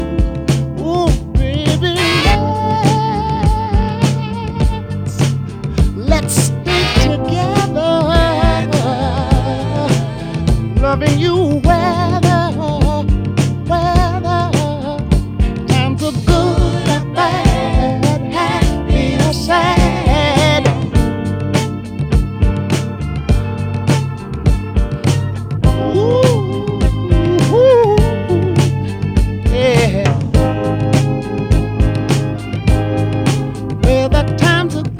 Жанр: Соул